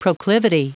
Prononciation du mot proclivity en anglais (fichier audio)
Prononciation du mot : proclivity